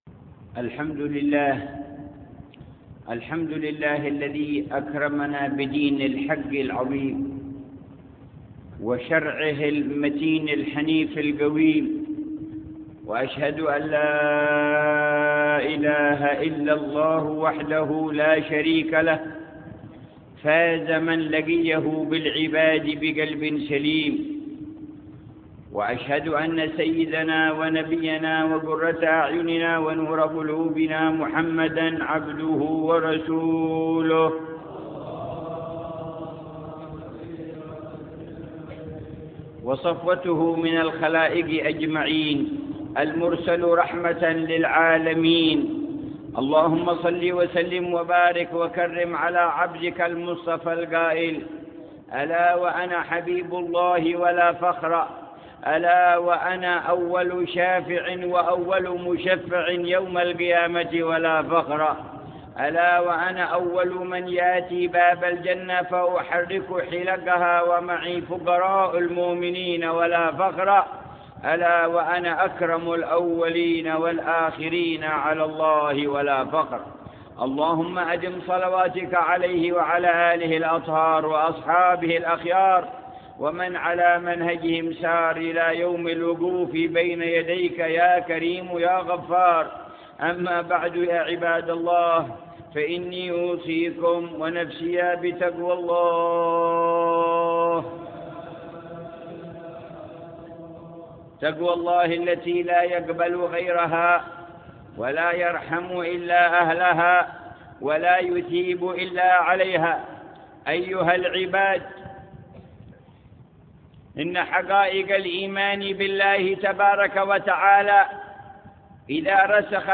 خطبة الجمعة في جامع مشطة - حضرموت.